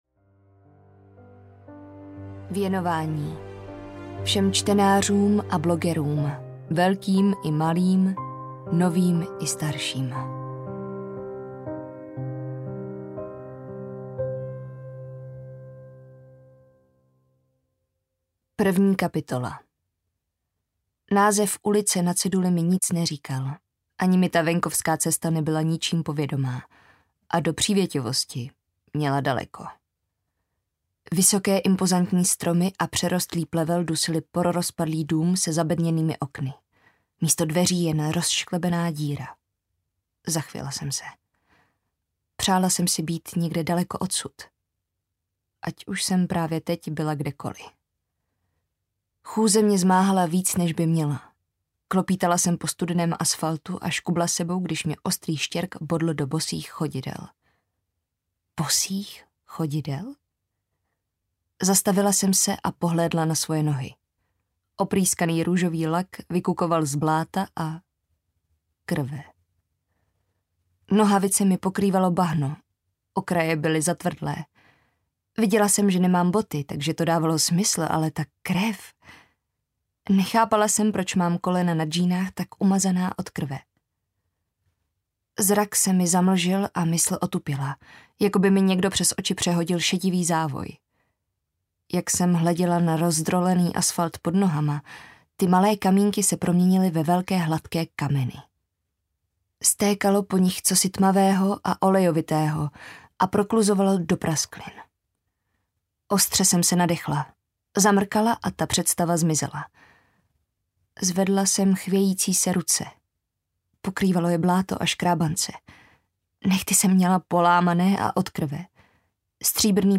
Neohlížej se audiokniha
Ukázka z knihy